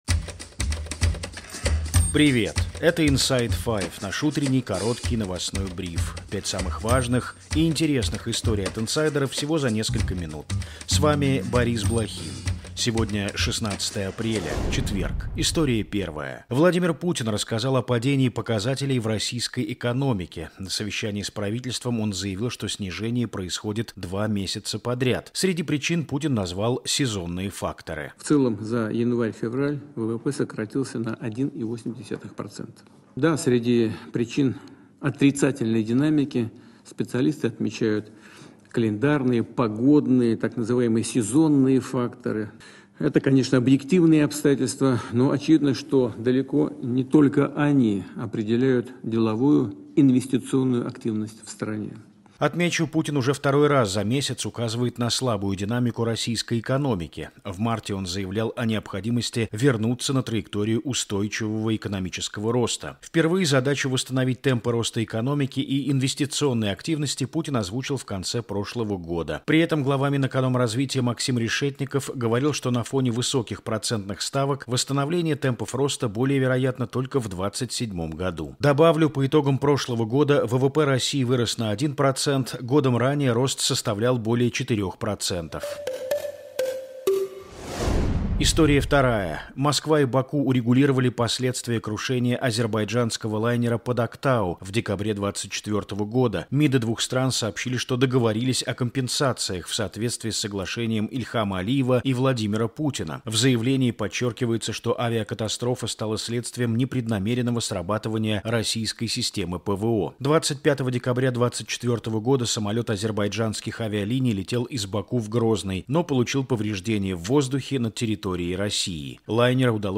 Эфир ведёт